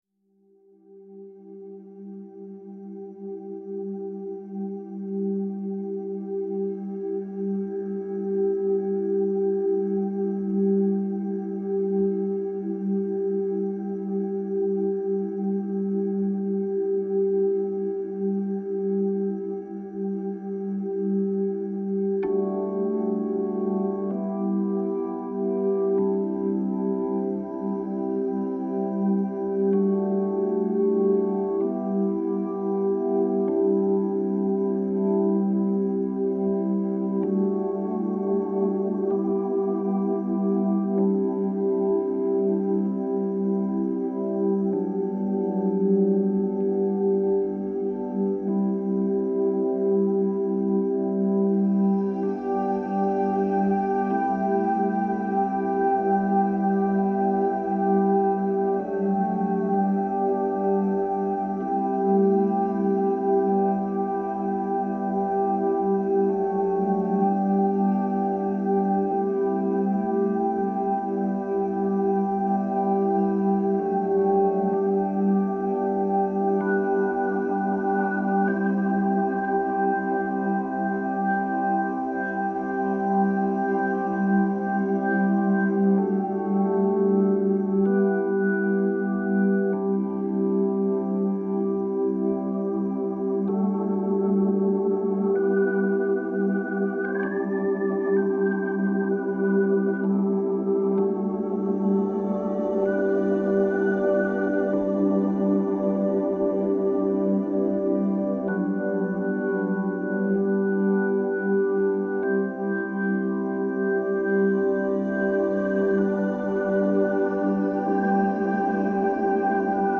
Música de fondo